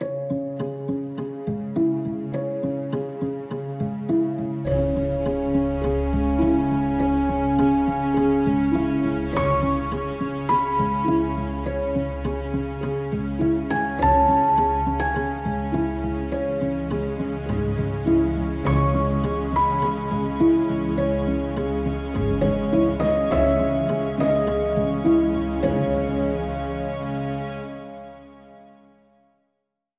I love this moody stuff!